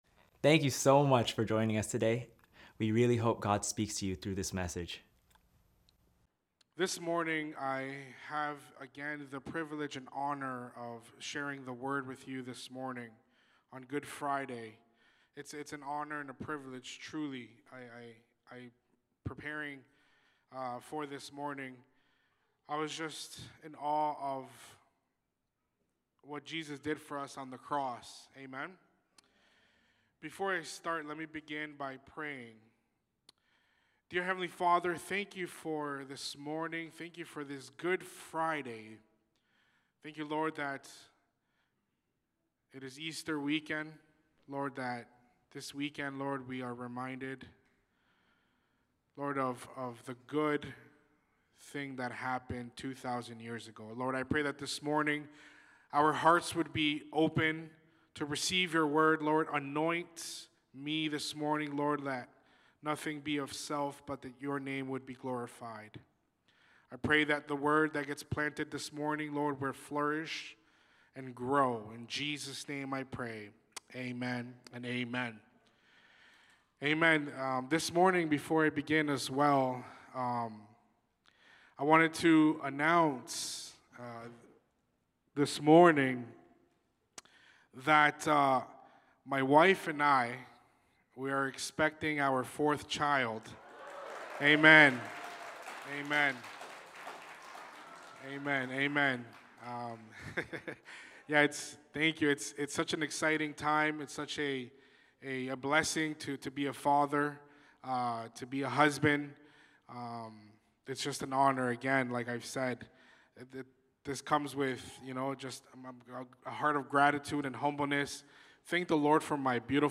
Good Friday Morning Service
Lighthouse Niagara Sermons